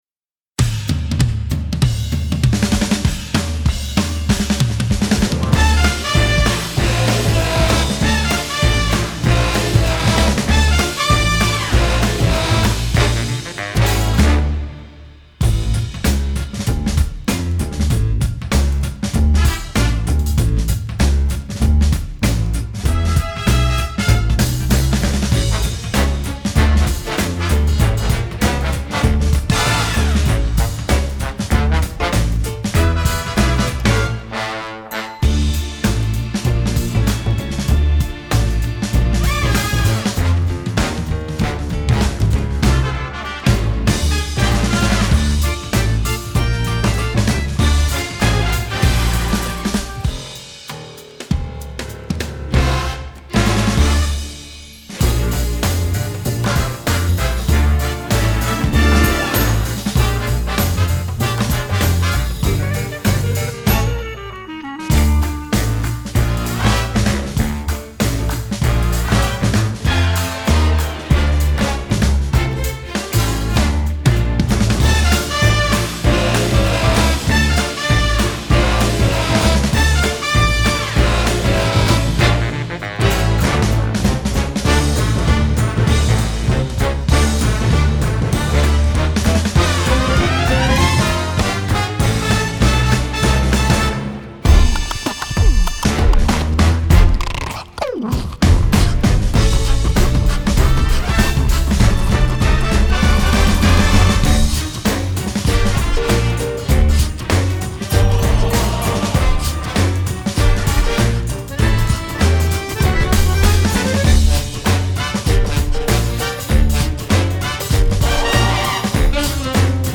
Karaoke Instrumental instrumental music
موسیقی بیکلام